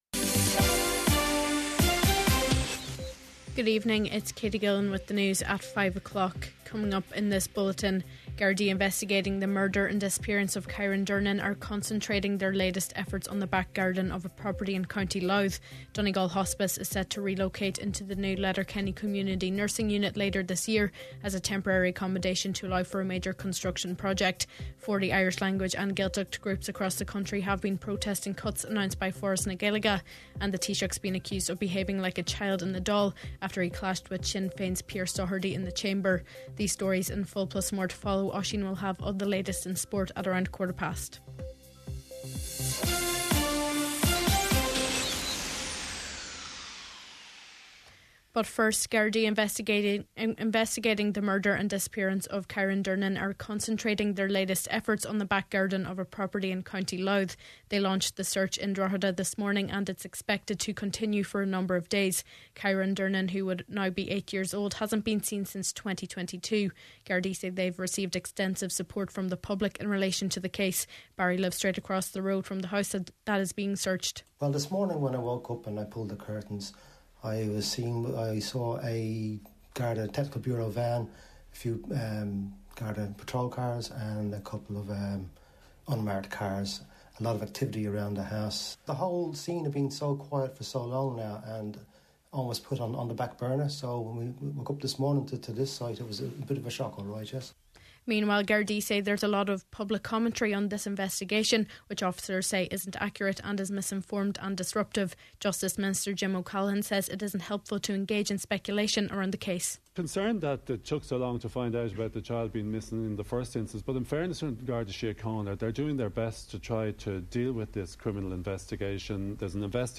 Main Evening News, Sport and Obituaries – Wednesday February 26th